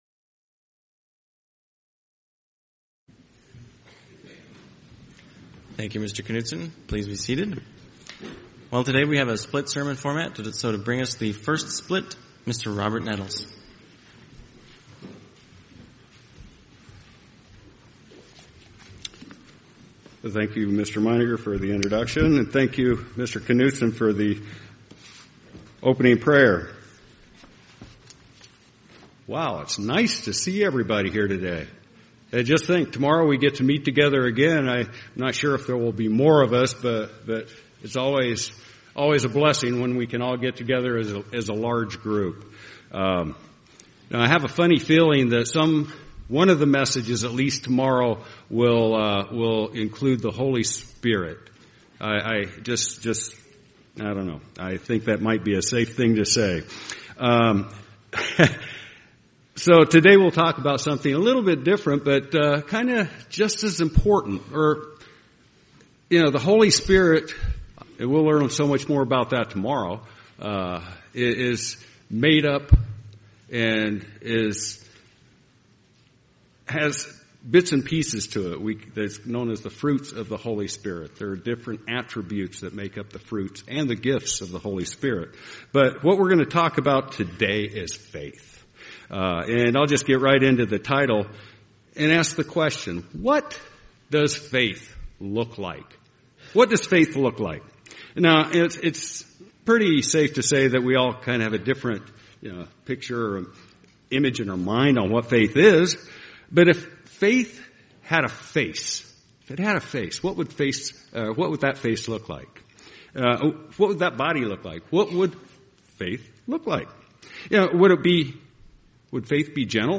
Sermons
Given in Phoenix East, AZ